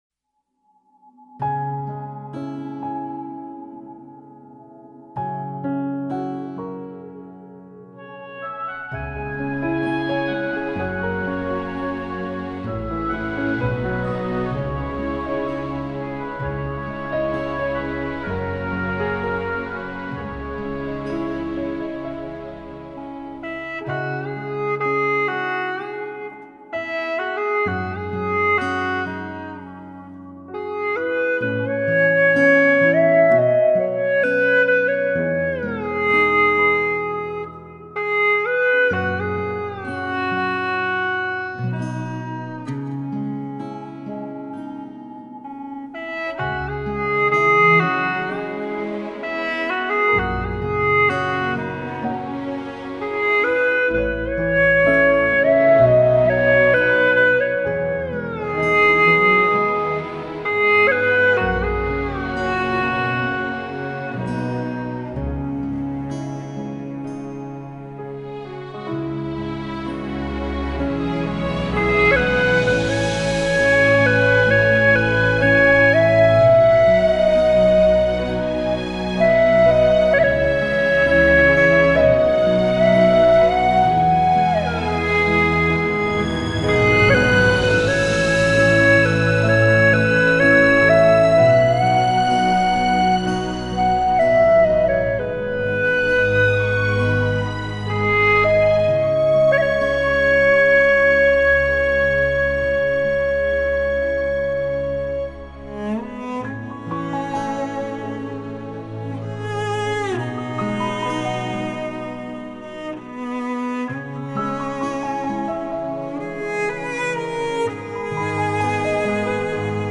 调式 : E